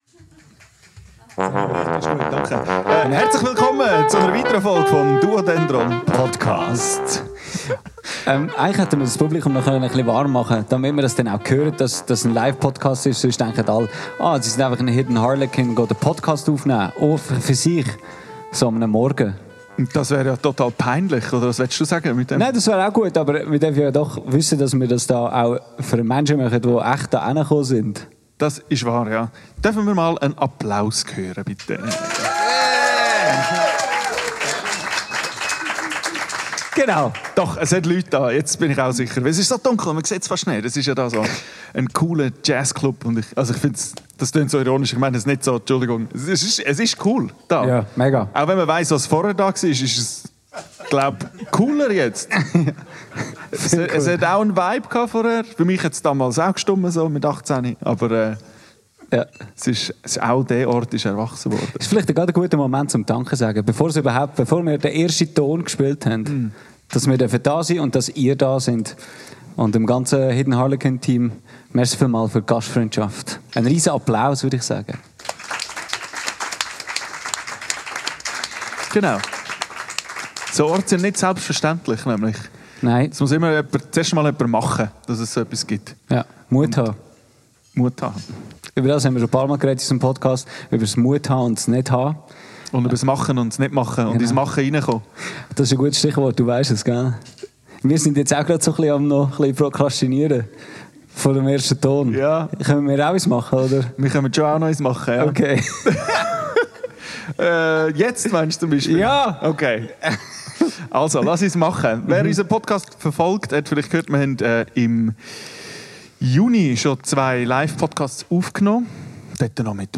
Es geht um die langen Bögen in der Musik. Aufgenommen am 03.09.2025 im Hidèn Harlekin in Zug.